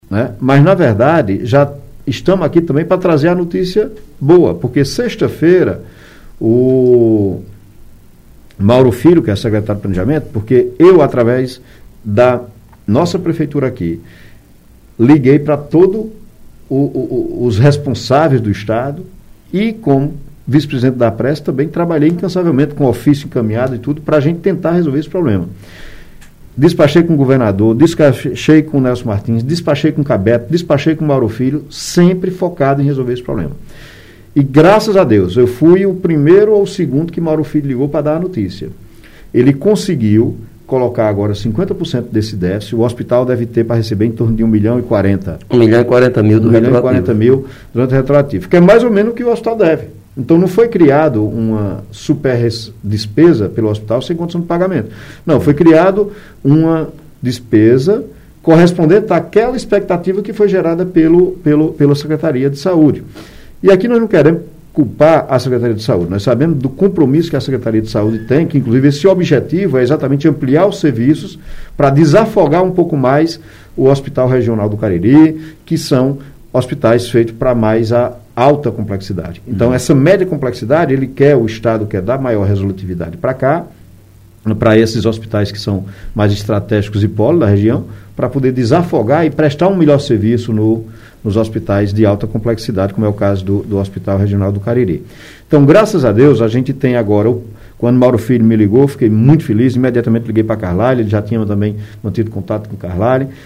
Atualizada [24-08]: Em entrevista na Rádio Cultura [FM 96.3] na manhã desta segunda-feira, 23, o prefeito de Várzea Alegre, Zé Helder, MDB, anunciou que o secretário de Planejamento do Ceará, Mauro Filho, irá enviar um valor para 50% do déficit financeiro do Hospital São Raimundo.
ZE-HELDER-ENTREVISTA.mp3